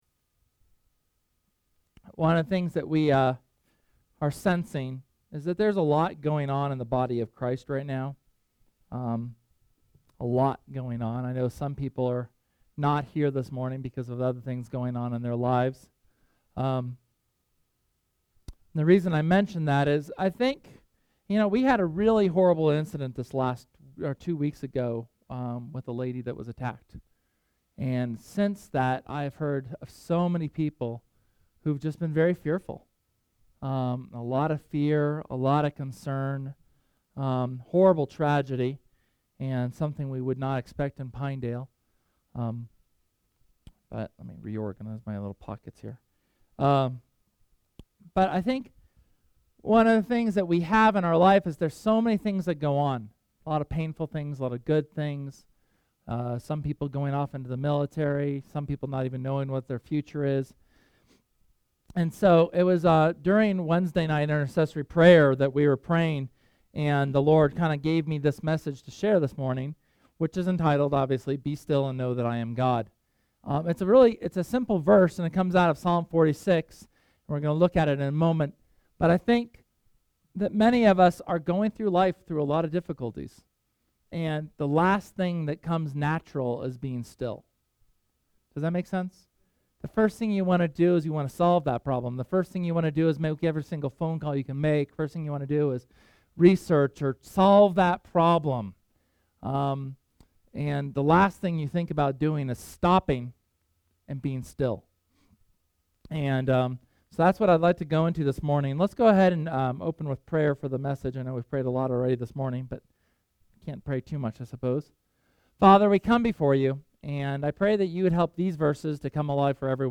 Sunday sermon from June 8th on Psalms 46.